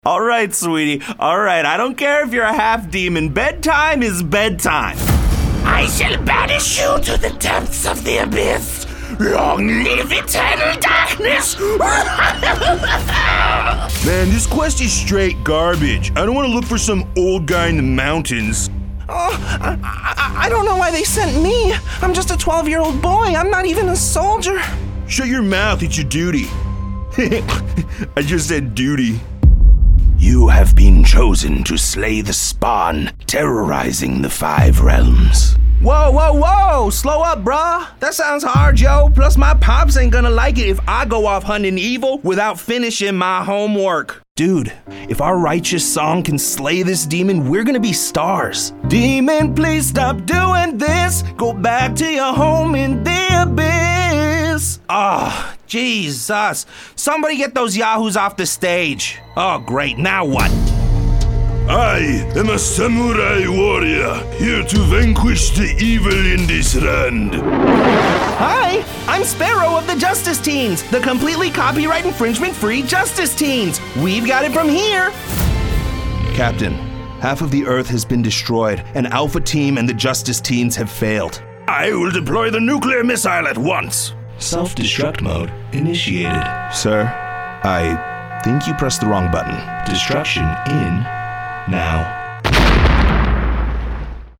Teenager, Young Adult, Adult
Has Own Studio
standard us | natural